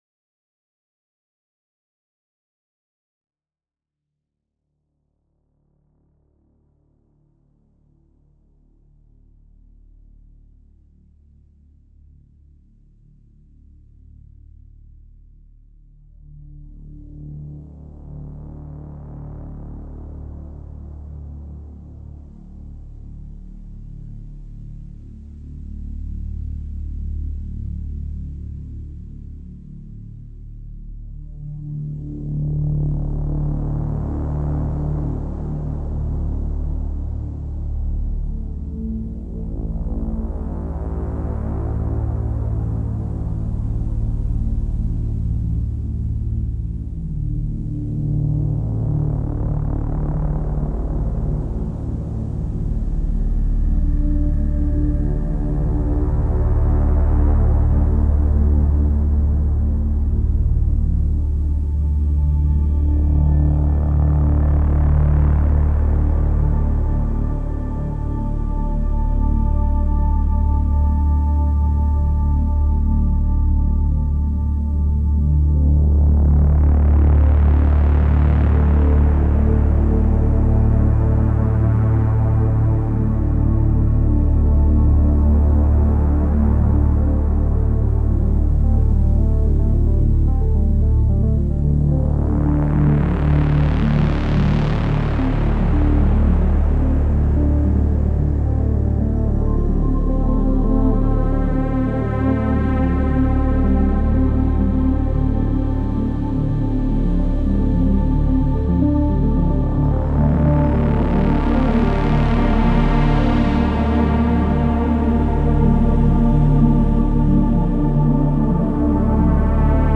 Elaborated and progressive ambient/electronica track